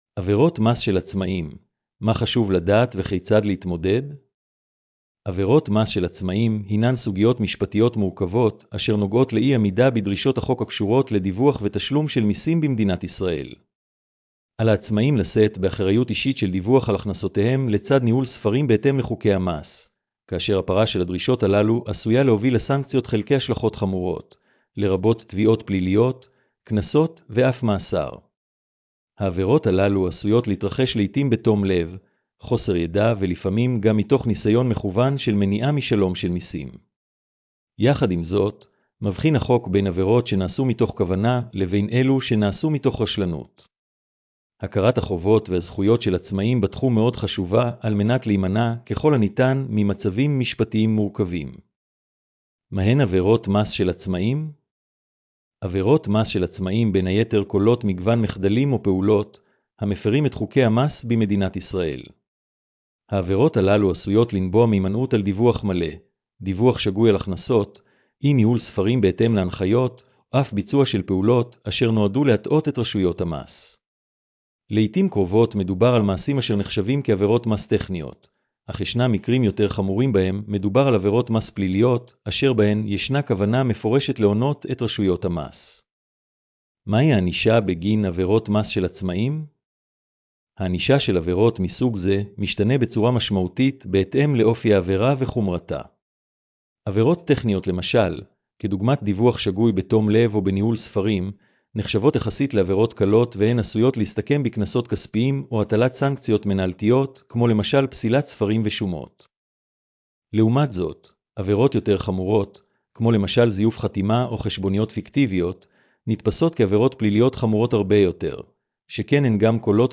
השמעת המאמר לכבדי ראייה: